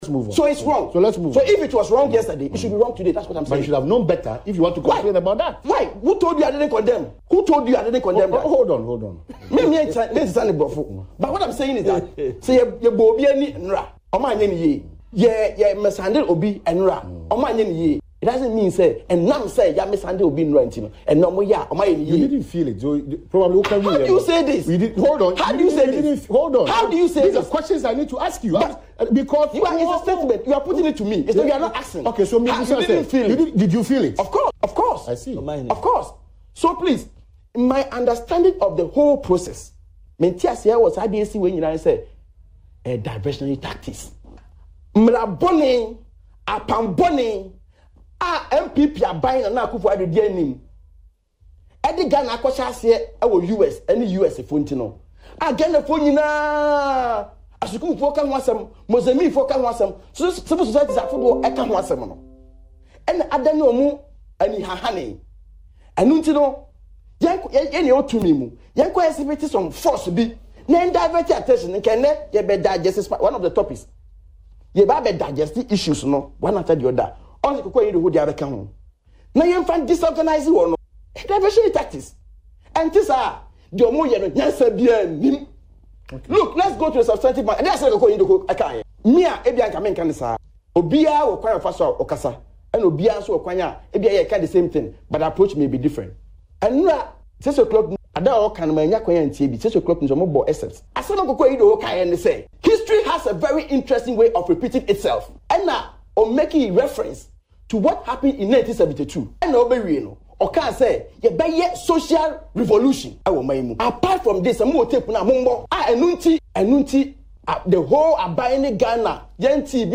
Mr. Akando was speaking on Adom TV’s Morning Show, “Badwam” Wednesday.